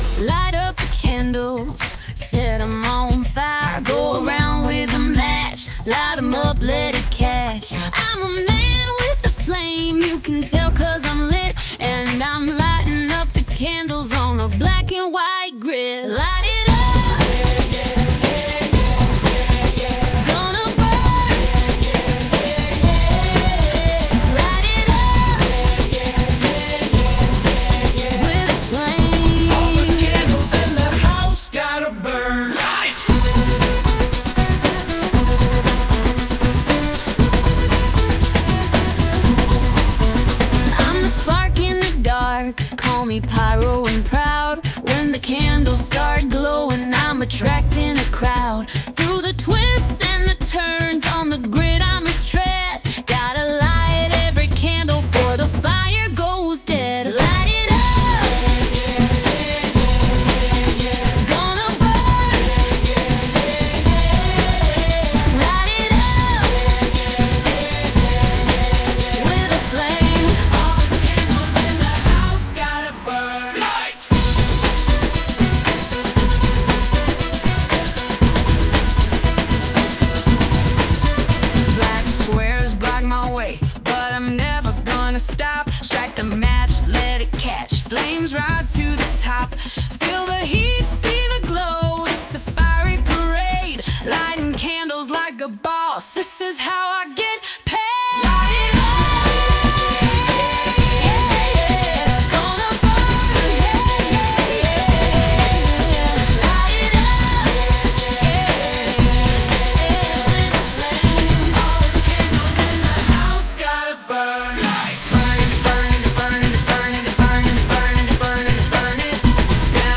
Country version
Sung by Suno